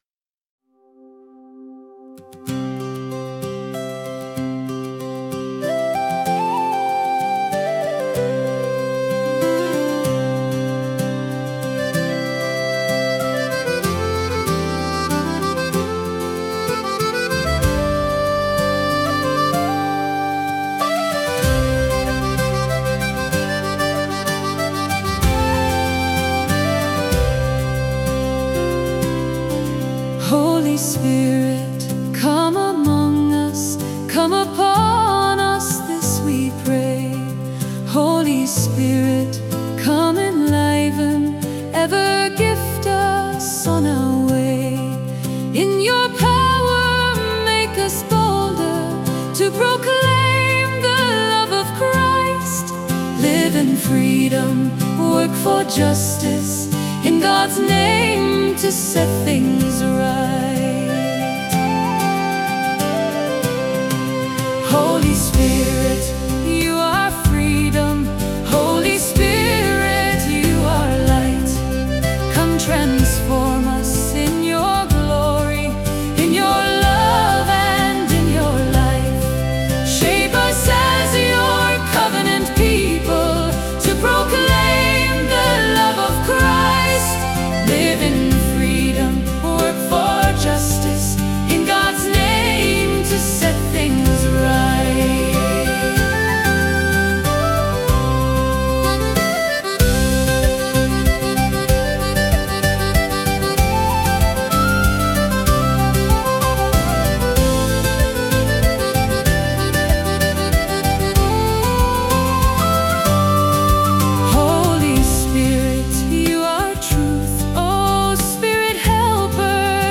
Holy-Spirit-Come-Among-Us-BASIC-VOCAL-C.1.mp3